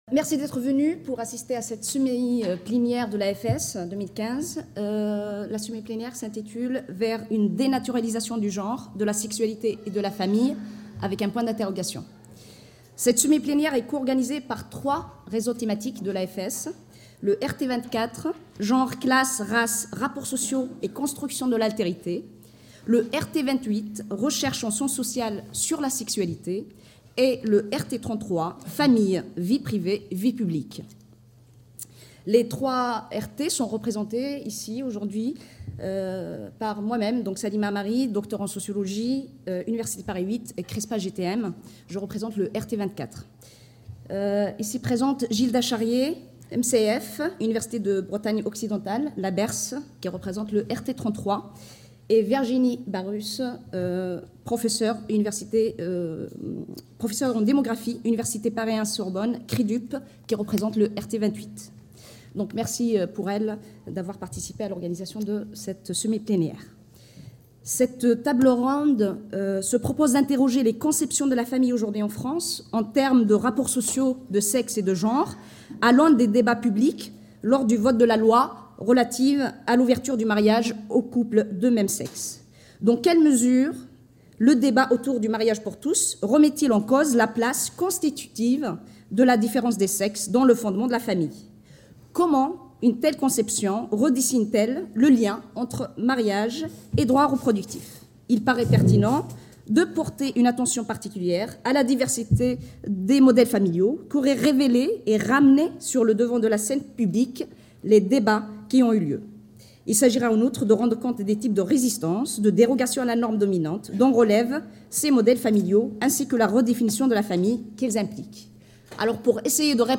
Cette table ronde se propose d’interroger les conceptions de la famille aujourd’hui en France en termes de rapports sociaux de sexe et de genre à l’aune des débats publics lors du vote de la loi relative